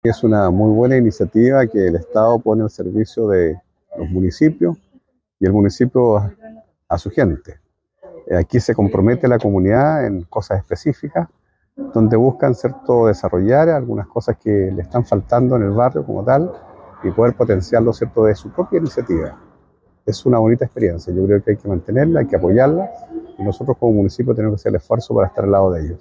Con gran éxito se desarrolló el Primer Encuentro de Seguridad Barrial, como parte del Plan de Confianza Social del Programa Quiero Mi Barrio, en la población García Hurtado de Mendoza, en Rahue Alto.
En tanto, el alcalde de la comuna, Jaime Bertín, brindó un saludo a los presentes, donde manifestó el apoyo del municipio para la ejecución del Programa.